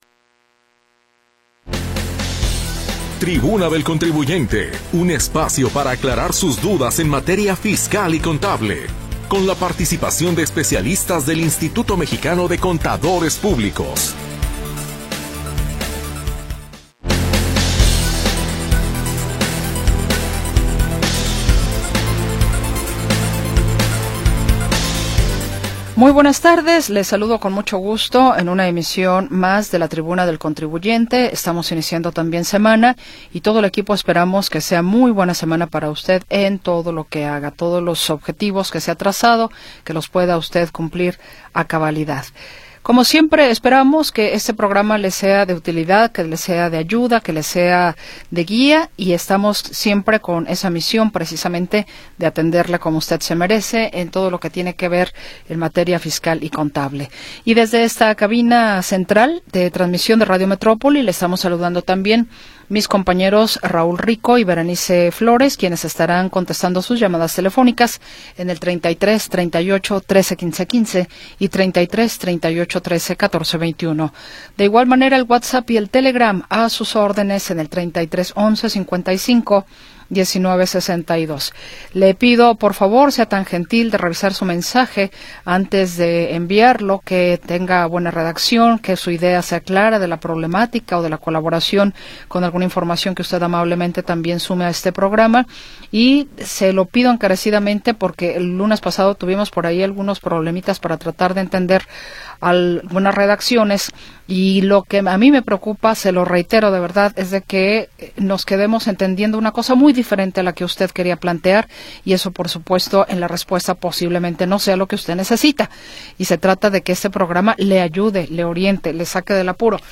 Orientación legal y contable con la participación de especialistas del Instituto Mexicano de Contadores.
Programa transmitido el 2 de Marzo de 2026.